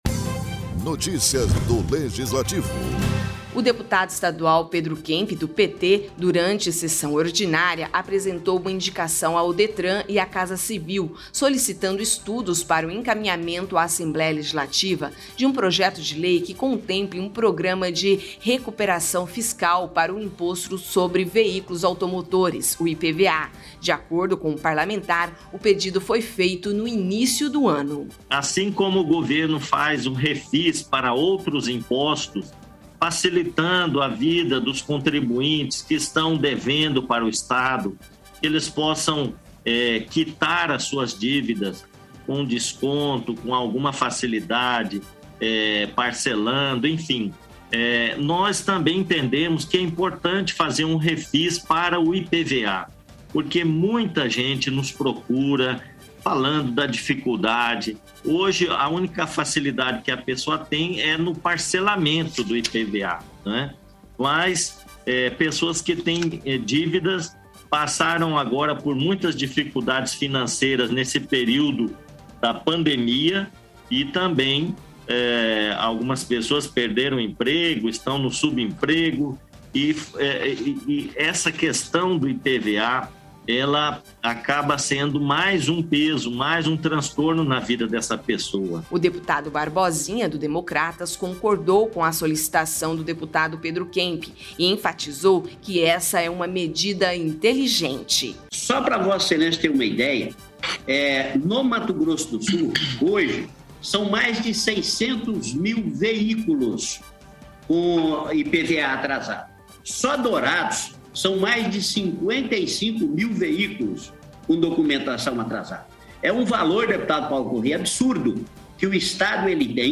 O deputado estadual Pedro Kemp (PT), durante sessão ordinária, apresentou uma indicação ao Detran e à Casa Civil solicitando estudos para o encaminhamento à Assembleia Legislativa de um projeto de lei que contemple um programa de recuperação fiscal para o Imposto sobre Veículos Automotores (IPVA).